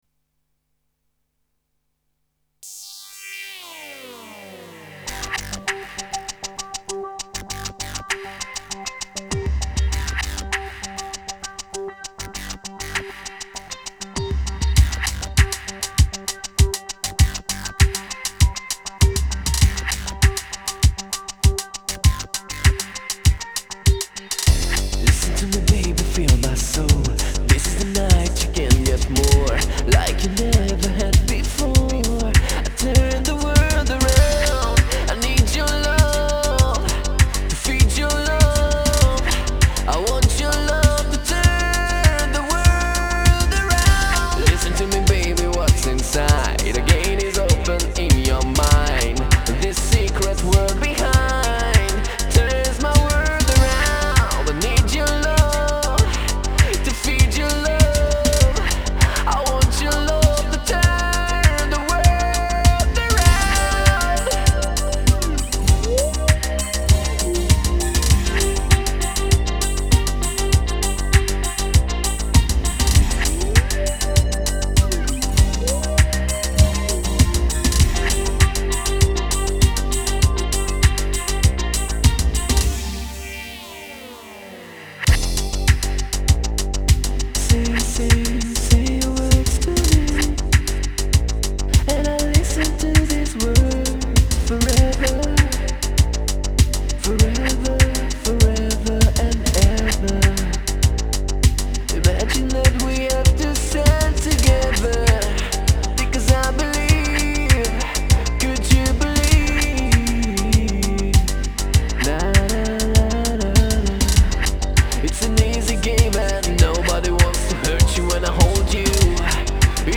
Vocoder Remix